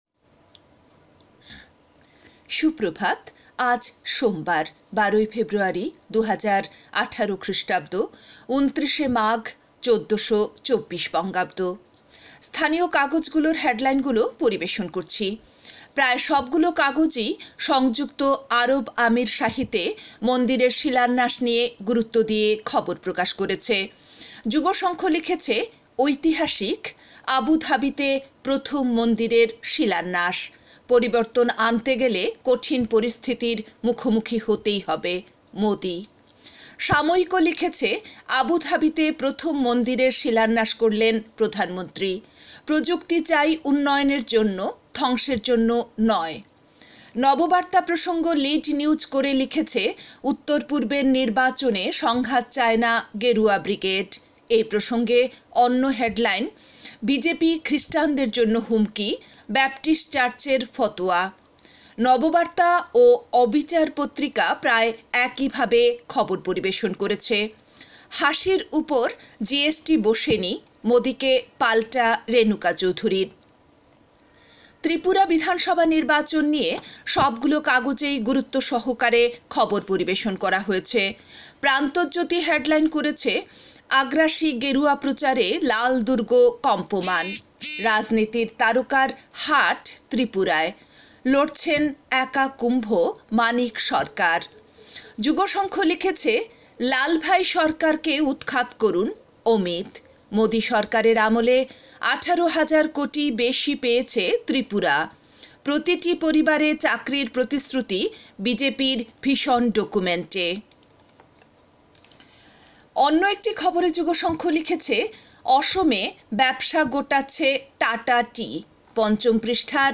A bulletin with all top headlines across categories.